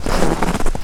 High Quality Footsteps
STEPS Snow, Walk 16-dithered.wav